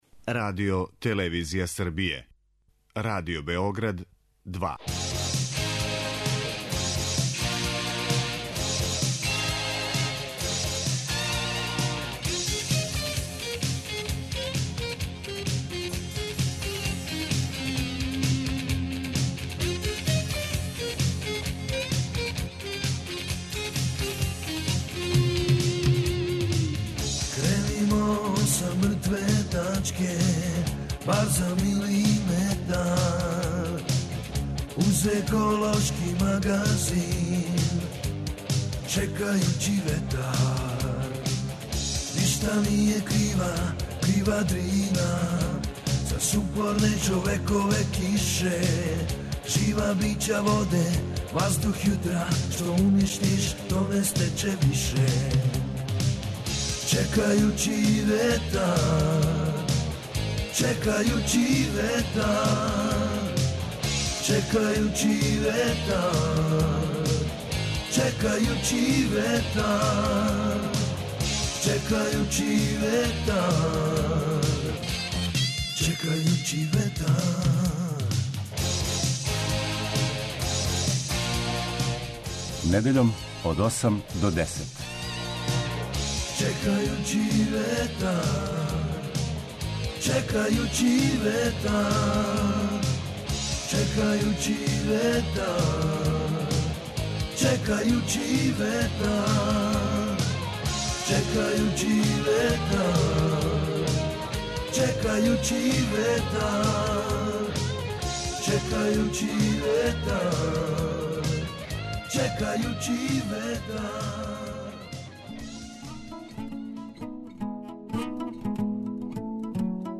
Еколошки магазин
Свака посета зоо-вртовима за њих је огроман стрес... а највише зла им наносе управо они који се куну у своју љубав. О чему се још говорило, шта мора да се зна о животињама - чућете у снимцима са трибине под насловом: Дивље животиње у заточеништву.